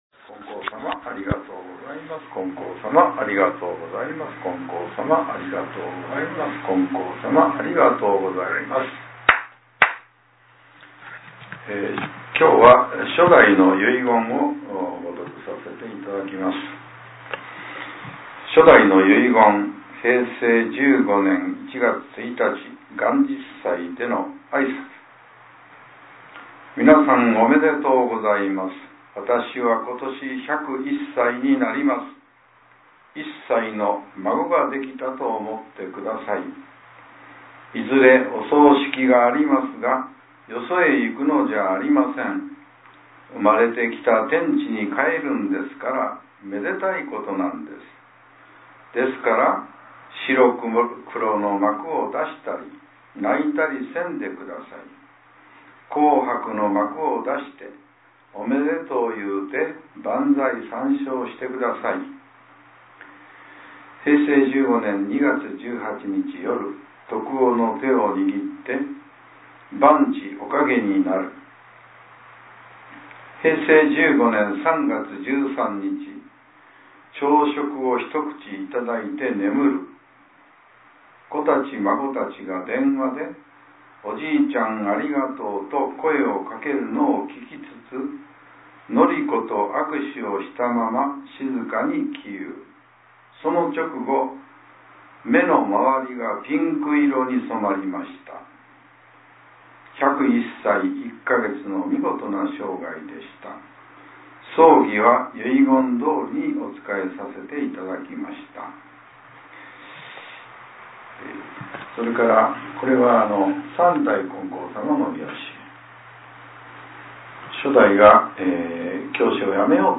令和７年８月２４日（朝）のお話が、音声ブログとして更新させれています。